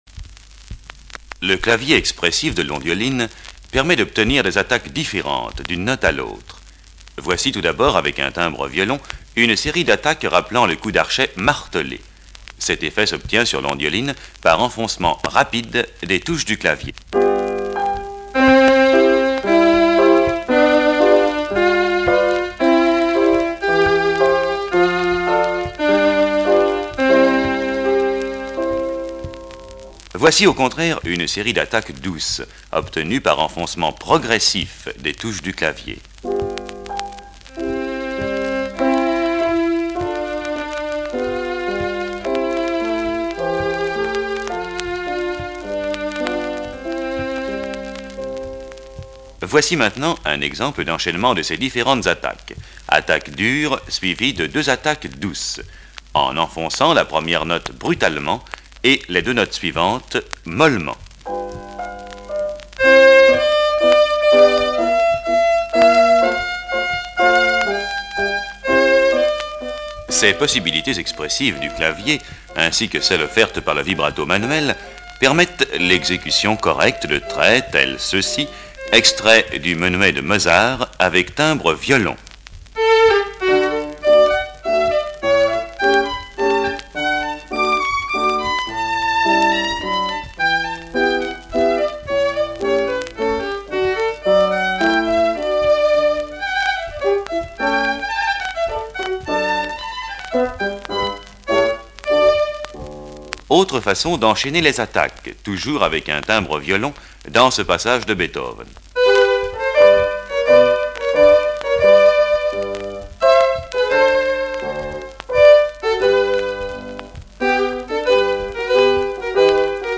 Petite démo des années 50:
ondioline.mp3